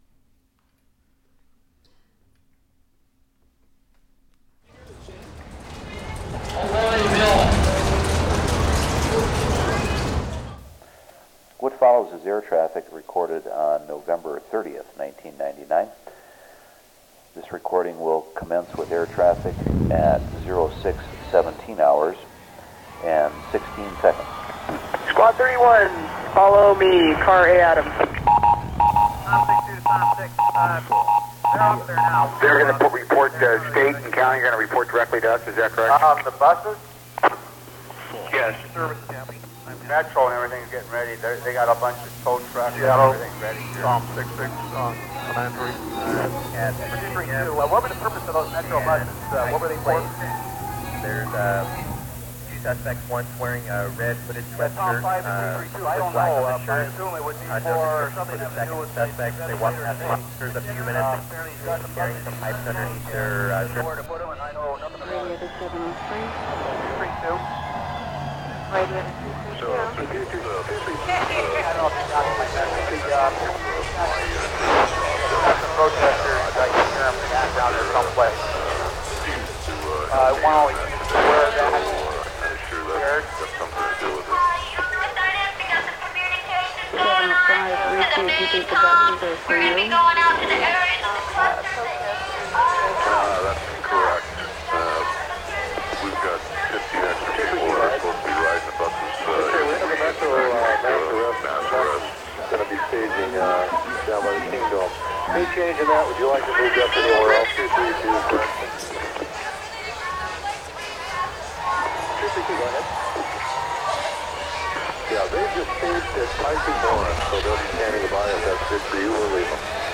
Venue Tramway, Glasgow